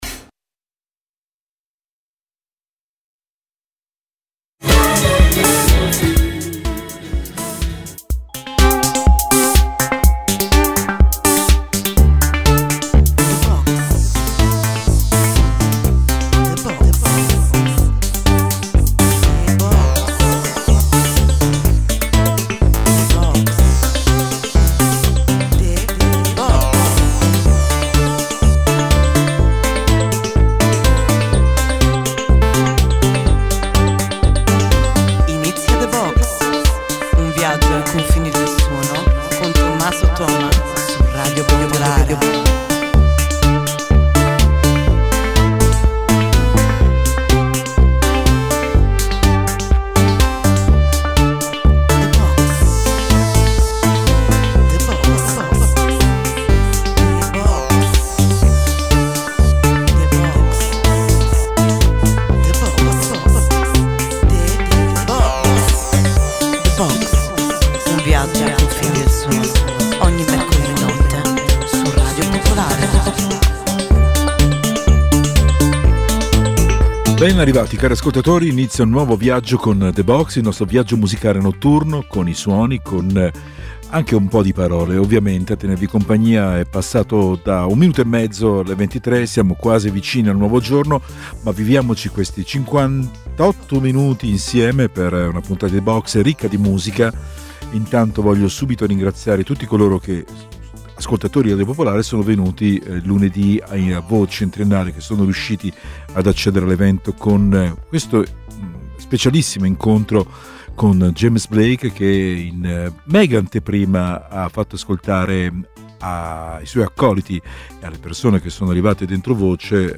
La sigla è un vero e proprio viaggio nel cuore pulsante della notte.